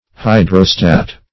Search Result for " hydrostat" : The Collaborative International Dictionary of English v.0.48: Hydrostat \Hy"dro*stat\, n. 1.